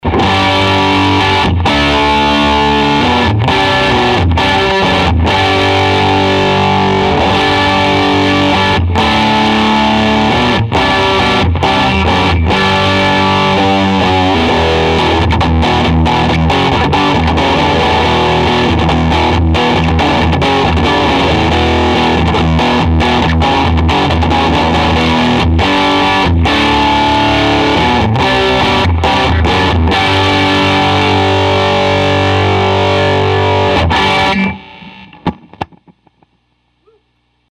Nasty as it will get with a 14K humbucker. TMB 12oclock. SS rectifier.
plexi_combo_cranked.mp3